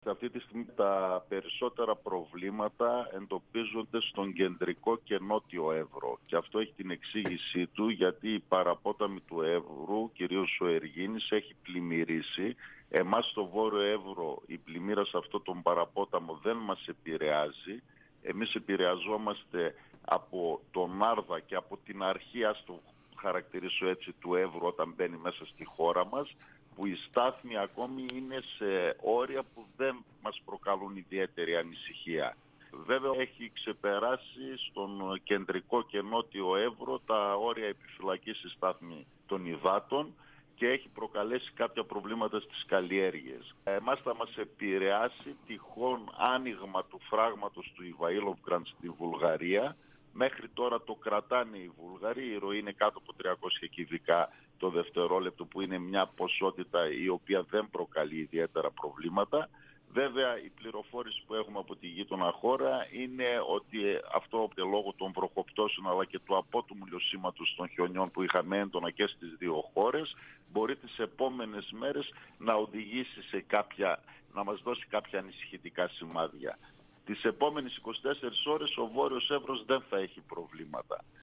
O δήμαρχος Ορεστιάδας, Βασίλης Μαυρίδης, στον 102FM του Ρ.Σ.Μ. της ΕΡΤ3
Συνέντευξη